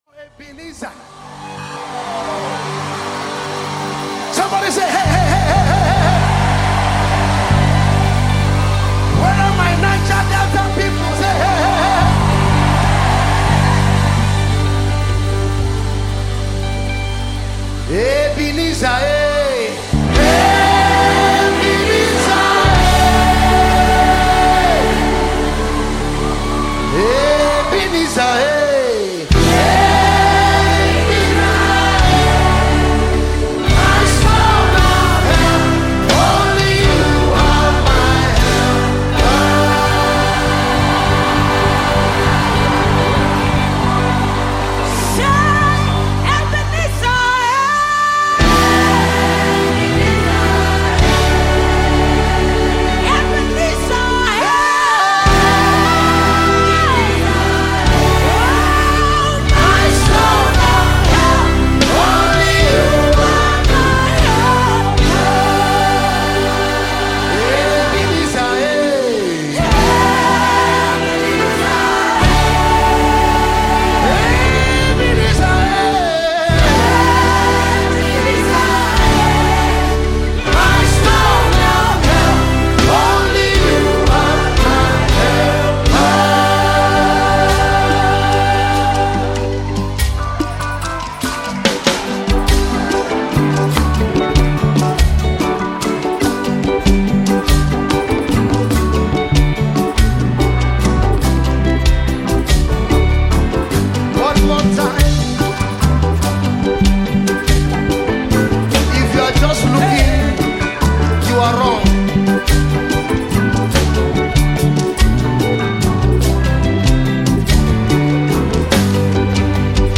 Gospel singer and trumpeter
powerful music